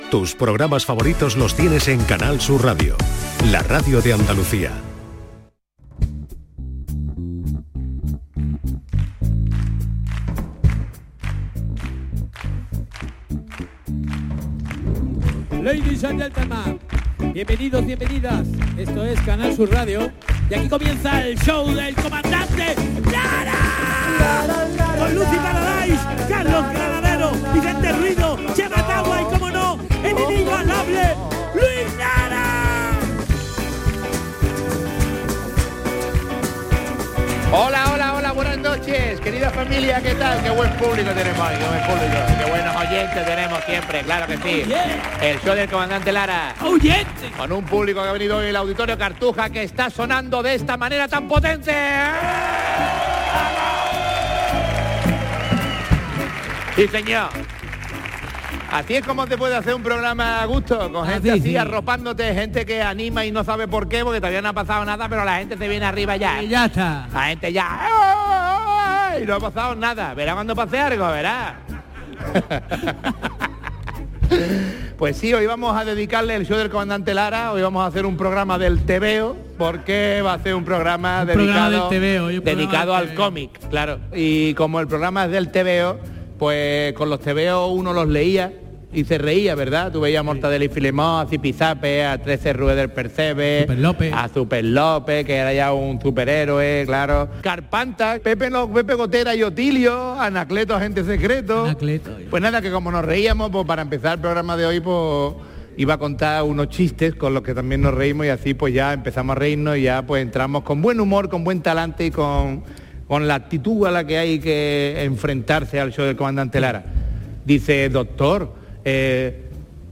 el humor más ingenioso y las entrevistas más originales. Canal Sur Radio | Domingos, justo después de la medianoche.